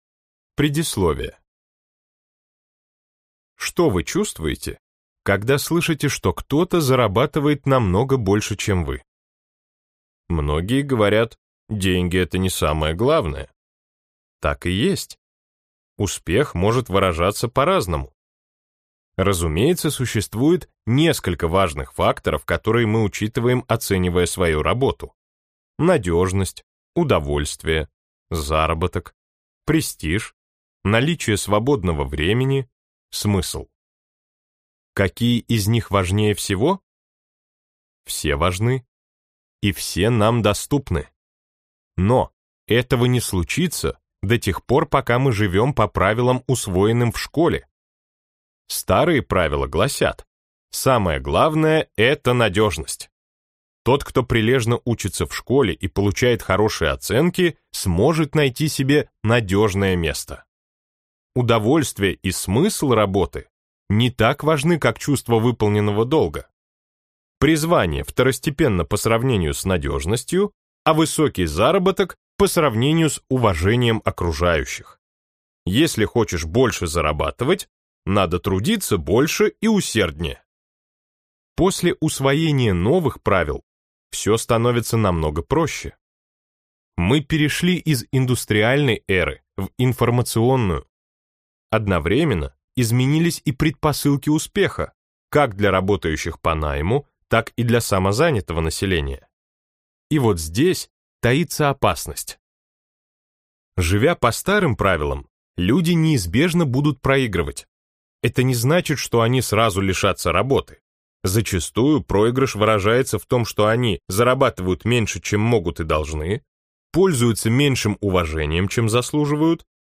Аудиокнига Пора зарабатывать больше! Как постоянно увеличивать доходы | Библиотека аудиокниг